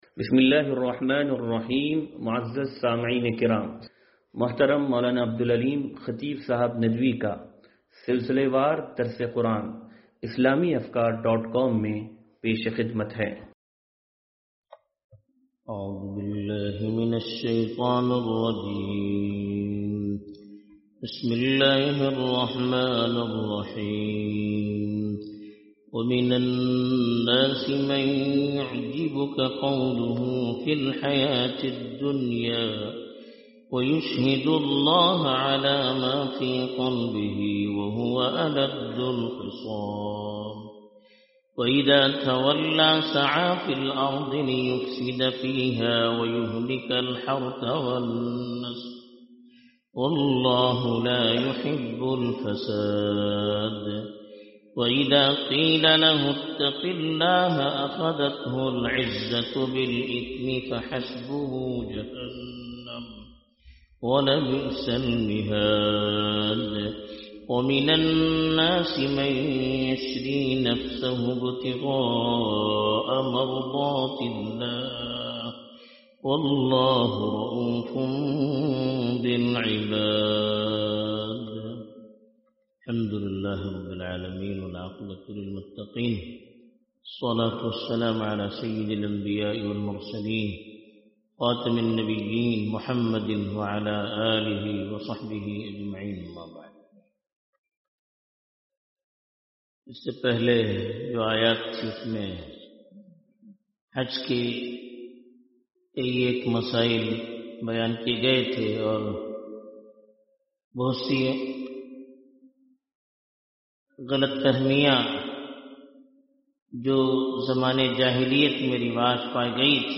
درس قرآن نمبر 0151
درس-قرآن-نمبر-0151-2.mp3